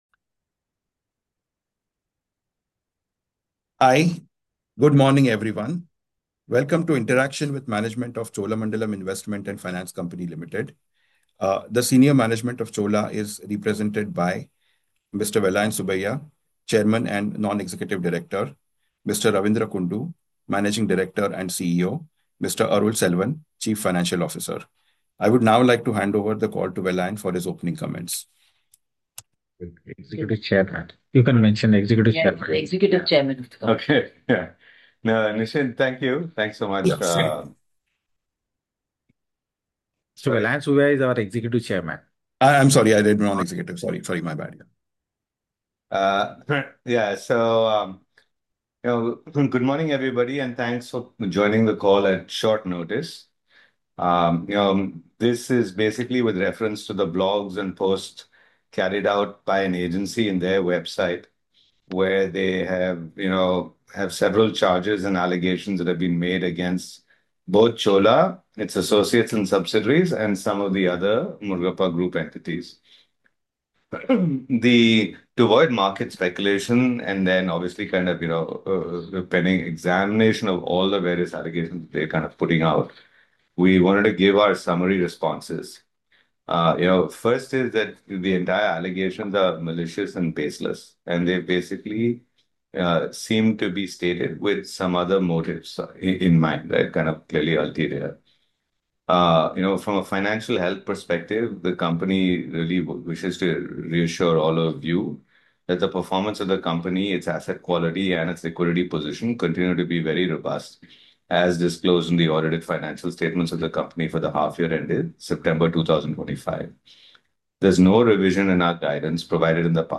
Investor call Audio Recording 23rd Dec 2025